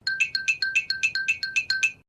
Звуки ксилофона
Мультяшный звон ксилофона